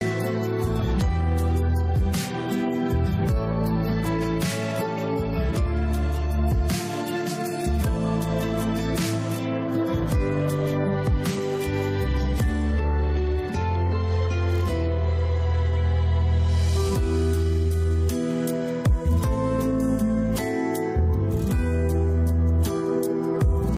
هوش مصنوعی bandlab برای حذف صدای خواننده
نمونه‌ ساخته شده با Bandlab: